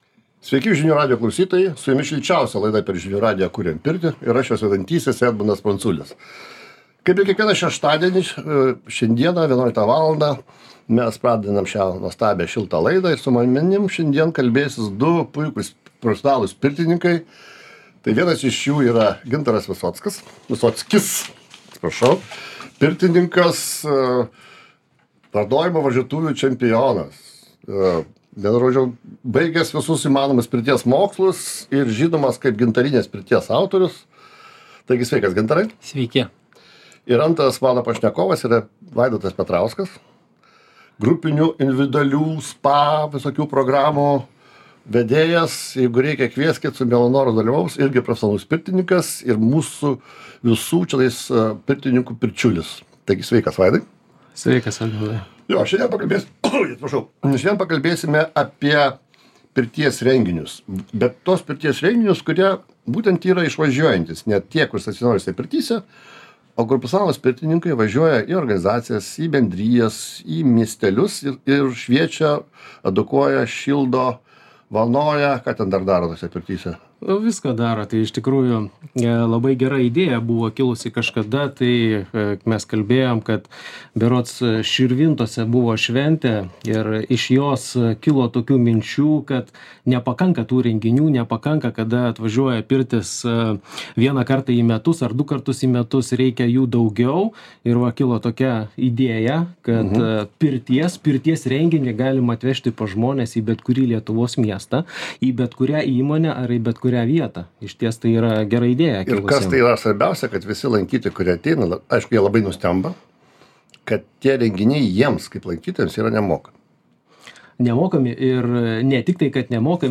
Renginiai „Kuriam pirtį" – kaip jie gimė, kaip jie vyksta, kas ir kur gali juos sutikti. Pokalbis su profesionaliais pirtininkais